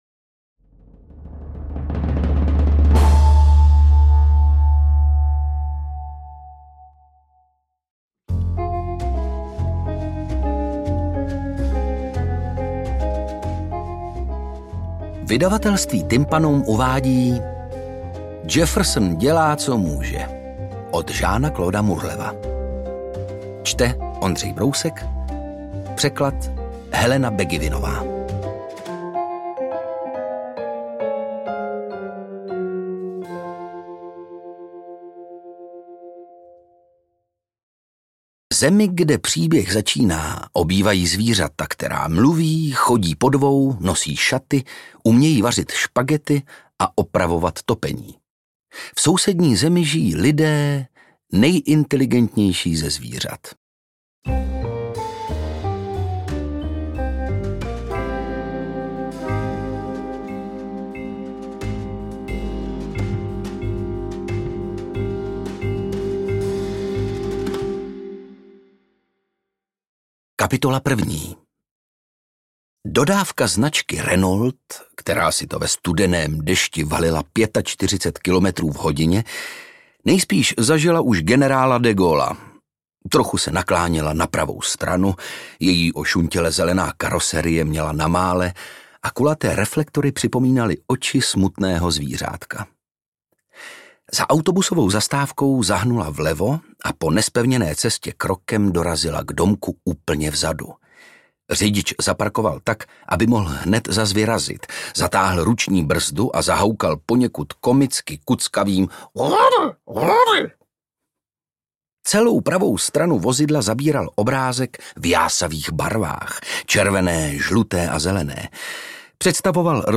Interpret:  Ondřej Brousek
AudioKniha ke stažení, 20 x mp3, délka 5 hod. 40 min., velikost 312,5 MB, česky